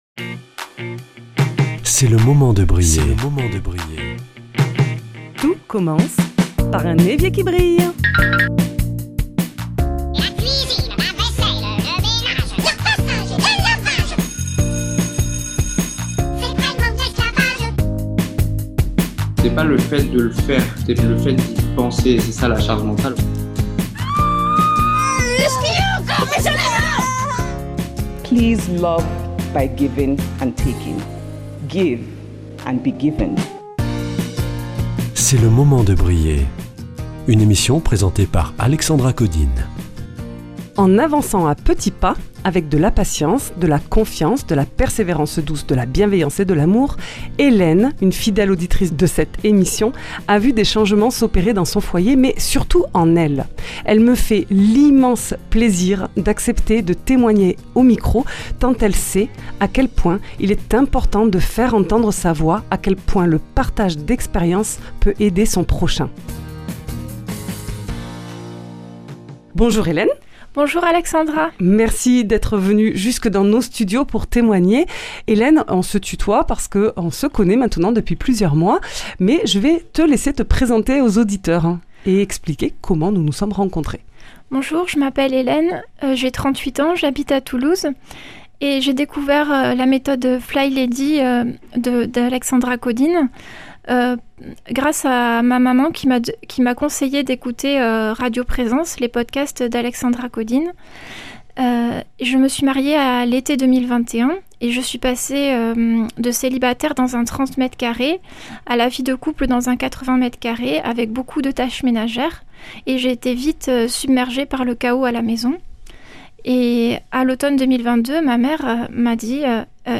Une auditrice vient témoigner du pouvoir des 31 petits pas